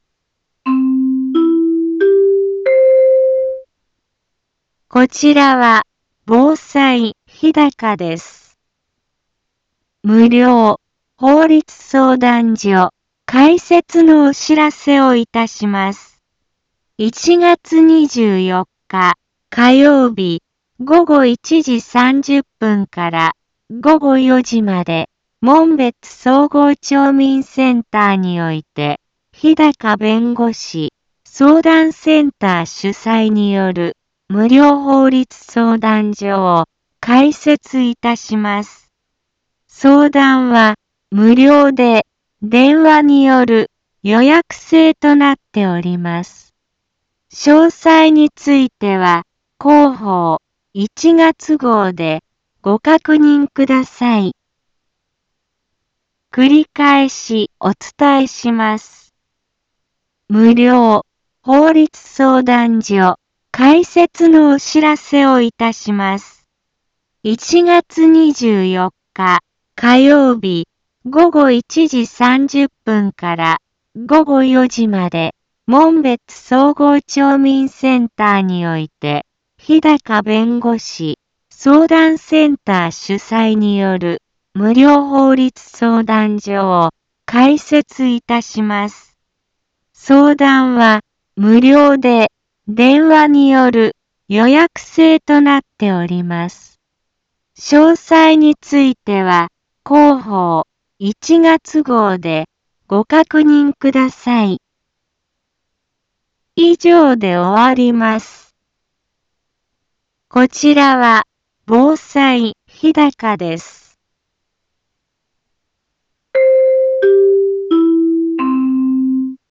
一般放送情報
Back Home 一般放送情報 音声放送 再生 一般放送情報 登録日時：2023-01-17 10:04:11 タイトル：無料法律相談会のお知らせ インフォメーション：こちらは防災日高です。 無料法律相談所開設のお知らせをいたします。